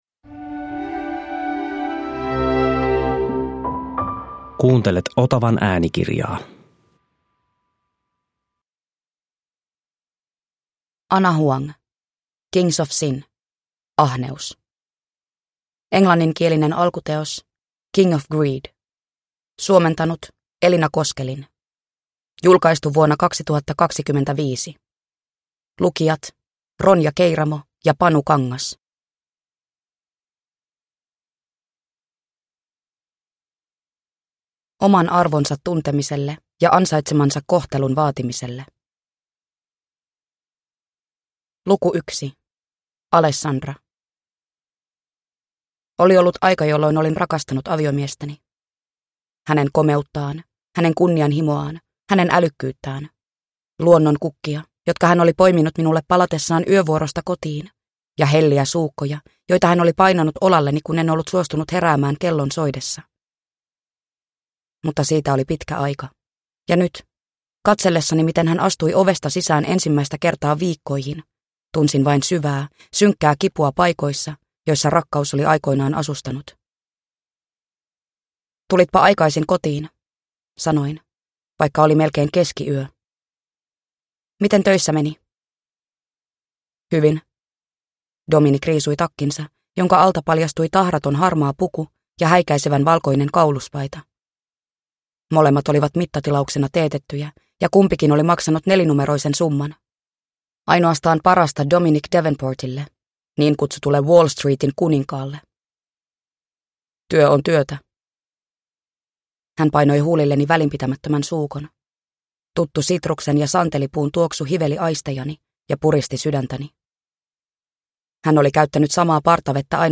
Kings of Sin: Ahneus (ljudbok) av Ana Huang